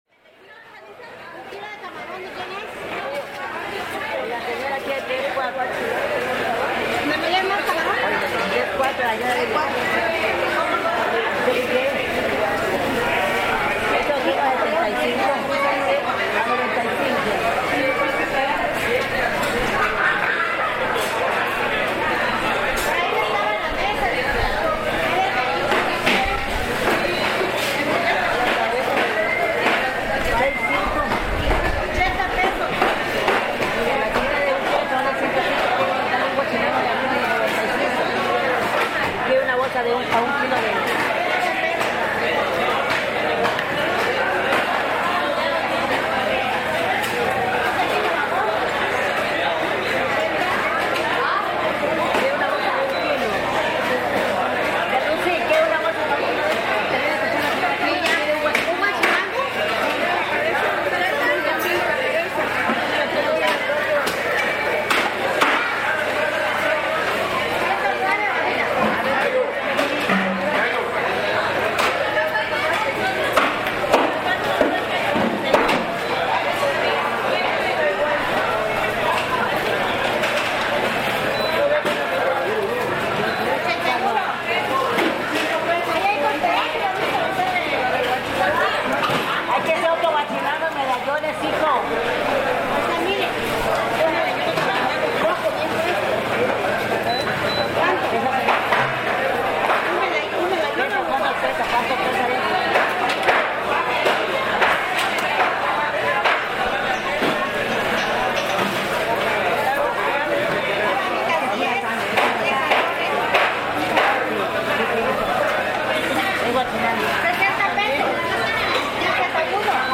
¿Cómo suena el Mercado del Norte de Tuxtla Gutierrez, Chiapas; Mexico? Los invitamos a conocer este establecimiento que se especializa principalmente en la venta de pescados y mariscos.
Equipo: Grabadora Sony ICD-UX80 Stereo